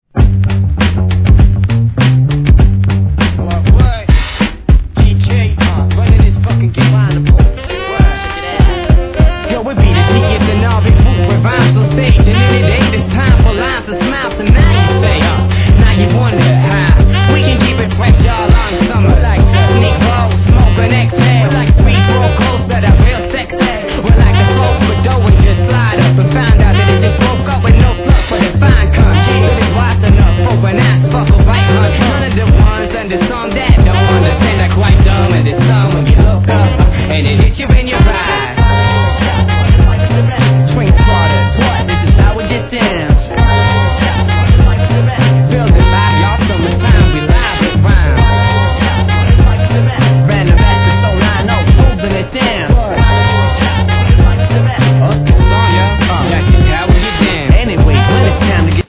HIP HOP/R&B
ホーンやピアノのPEACEFULLなプロダクションで流れるような2 MCによる掛け合いラップが素晴らしい!!